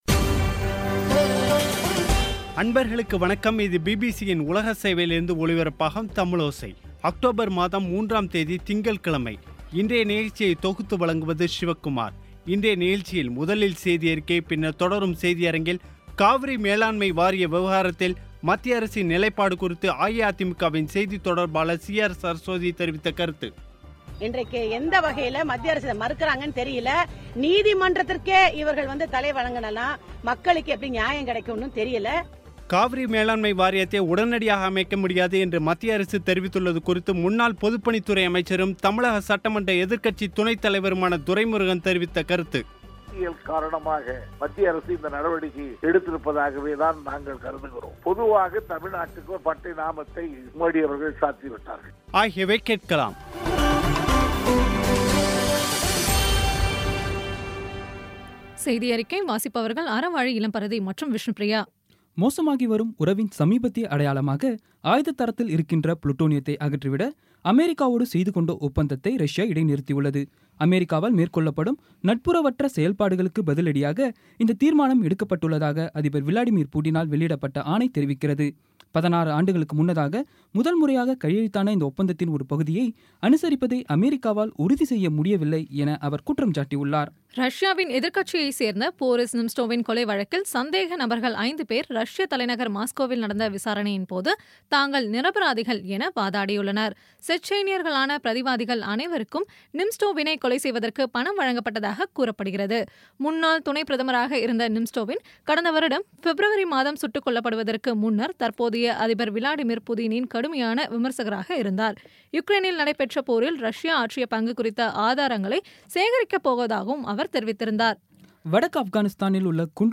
இன்றைய நிகழ்ச்சியில் முதலில் செய்தியறிக்கை, பின்னர் தொடரும் செய்தியரங்கில்